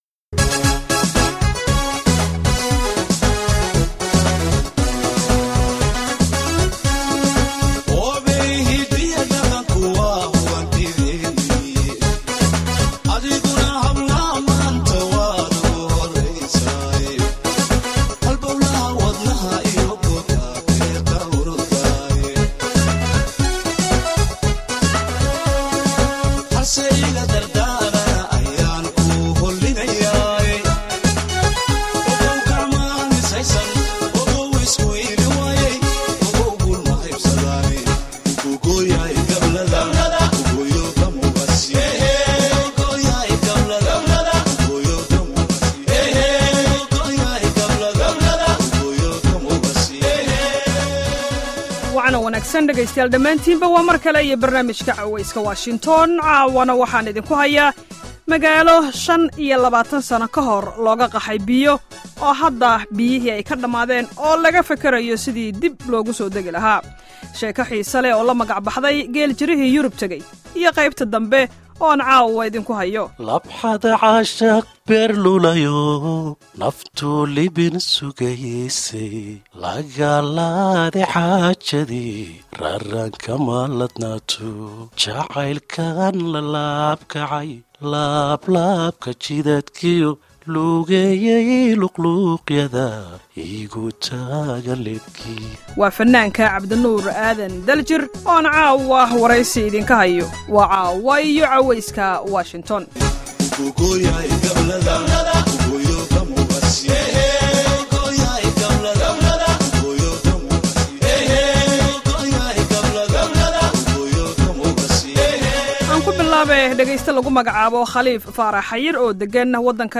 wareysi xiiso badan